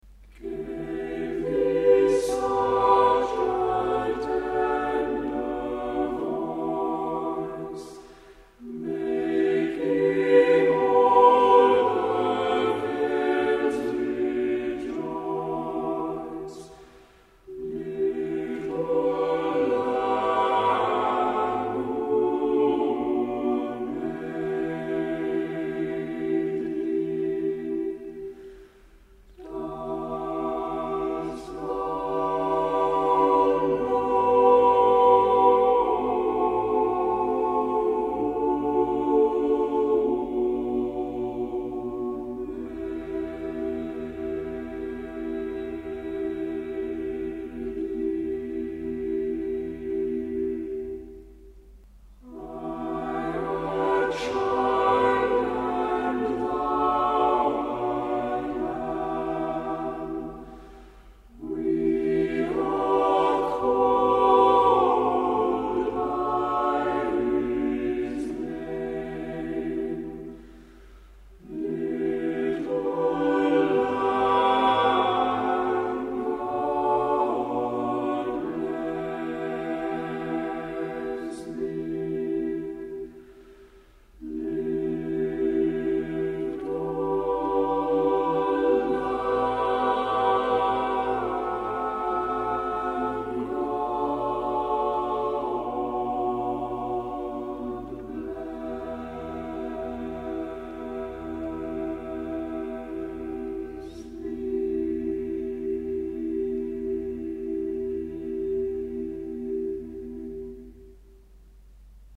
TAVENER, the lamb for chorus - LA TOUR, le charpentier.mp3